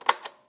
rewind up.aiff